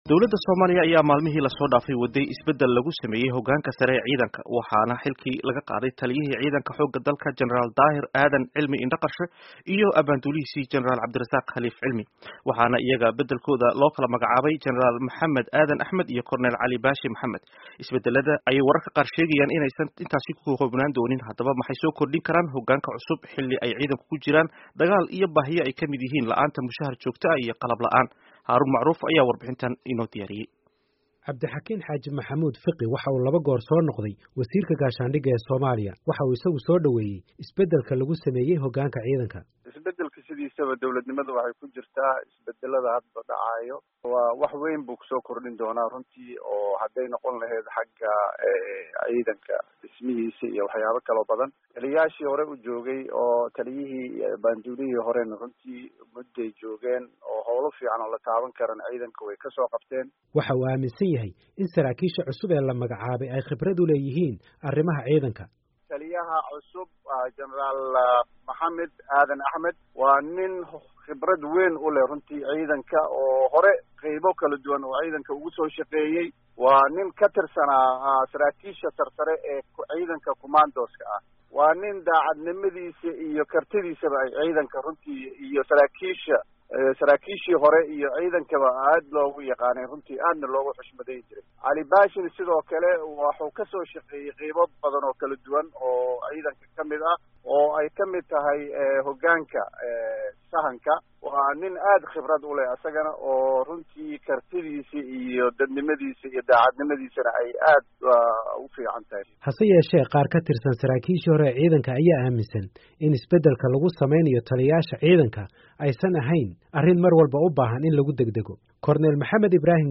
Dhegayso: Warbixin ku saabsan isbedellka hoggaanka Ciidanka Qaranka